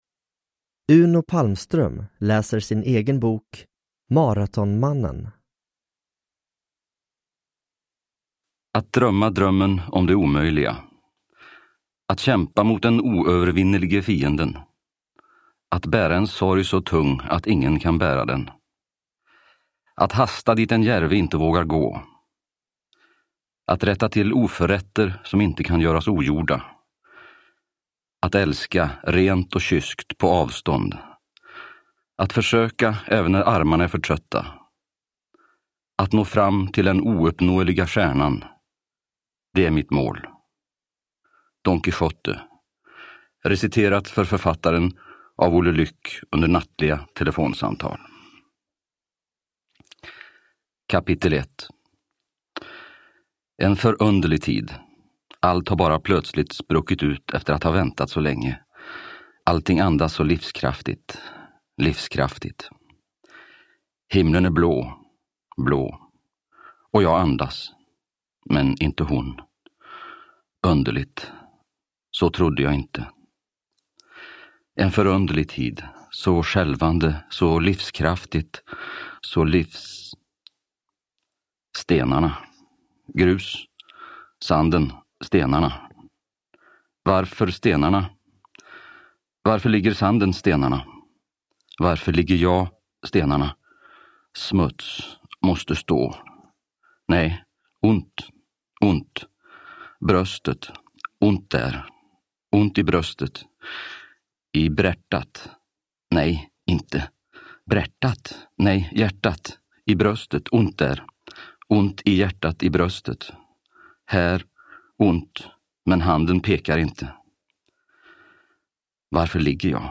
Maratonmannen – Ljudbok – Laddas ner